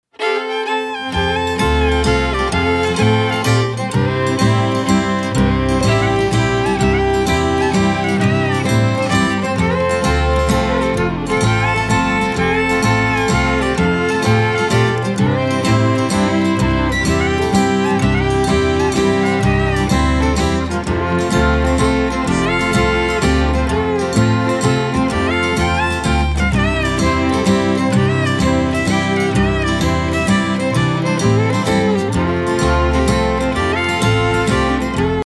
Genre: Country & Folk.